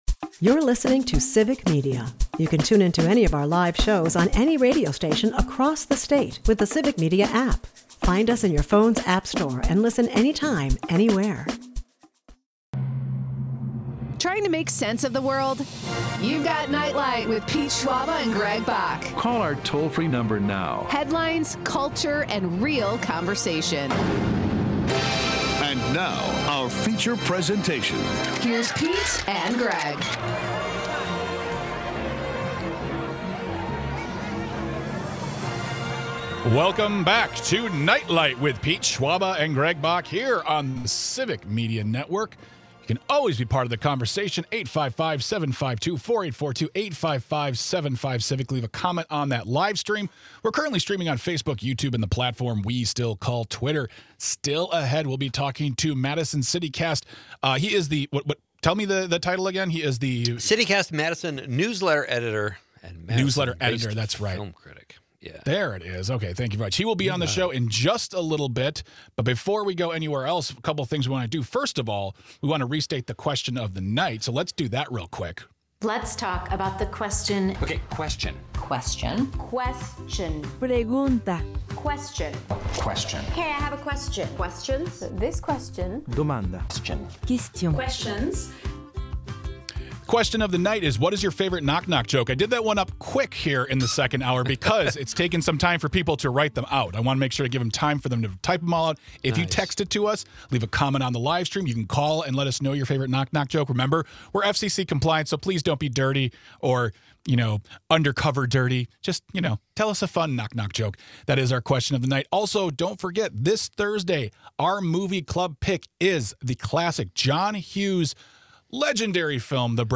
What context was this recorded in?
in studio to talk about the latest in movies. From big releases to indie gems, he shares what’s worth watching, where the industry is heading, and how he approaches reviewing films.